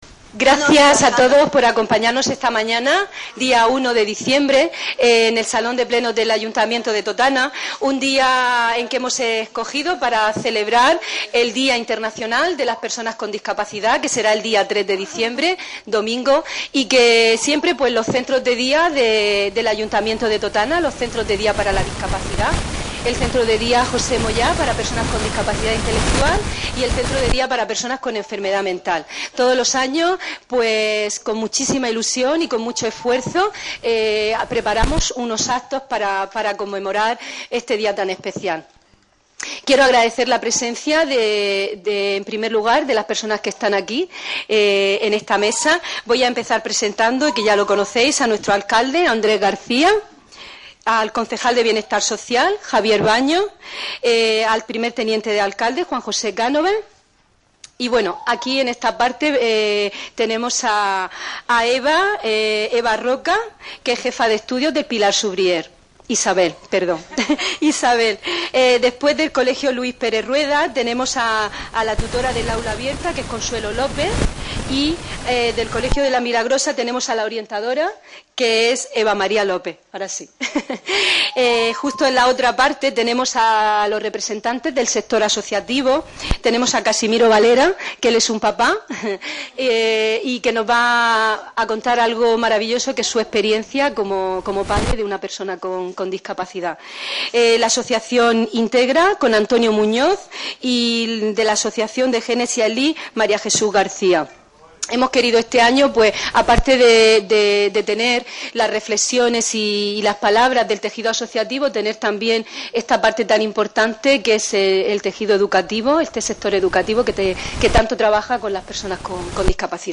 Totana conmemoró hoy el Día Internacional de la Discapacidad, que se celebra cada 3 de diciembre, con un acto institucional que tuvo lugar en el salón de plenos municipal y el desarrollo de diferentes actividades participativas en la plaza de la Balsa Vieja, en la que se dieron citad usuarios y profesionales de los centros de día de esta localidad.